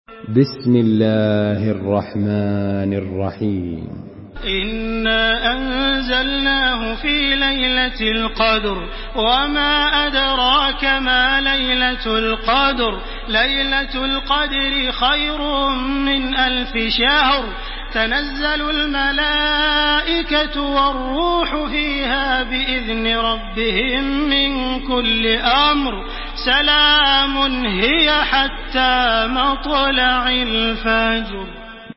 Listen and download the full recitation in MP3 format via direct and fast links in multiple qualities to your mobile phone.
Makkah Taraweeh 1429
Murattal